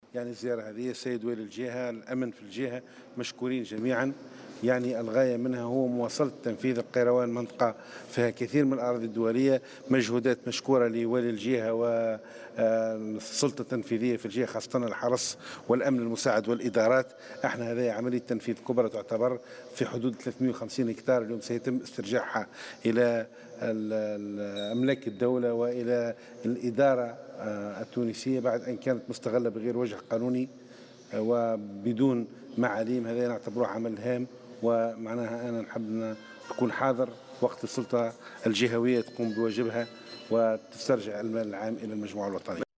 وأضاف في تصريح لمراسل "الجوهرة أف أم" على هامش زيارته للجهة أن هذه الأراضي كانت مستغلة دون وجه قانوني ودون معاليم، وفق تعبيره.